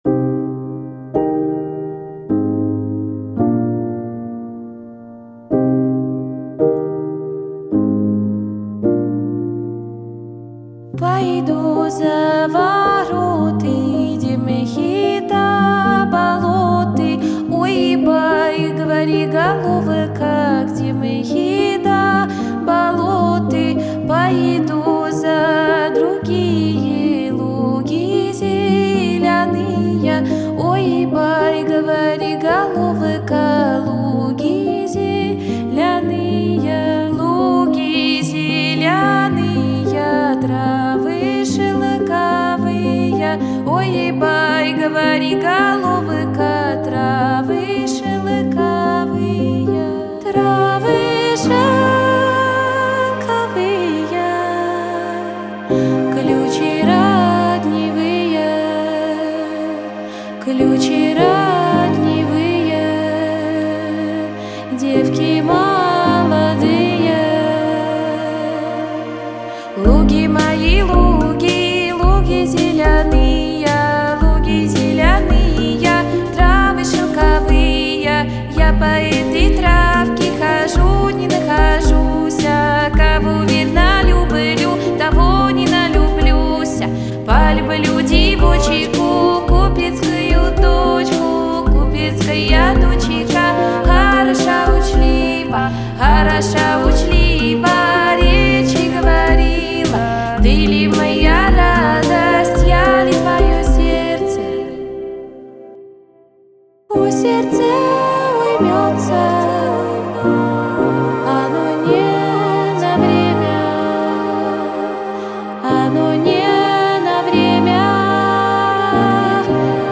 Категории: Русские песни, Поп.